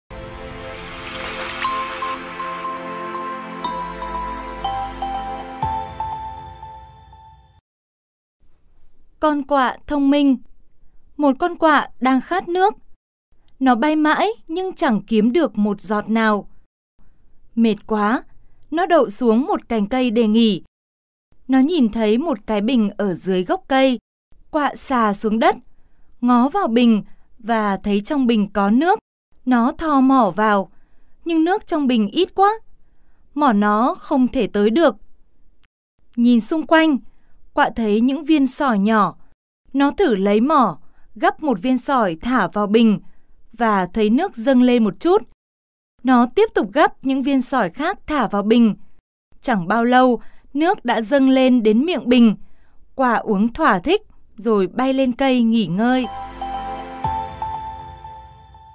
Sách nói | Con Quạ thông minh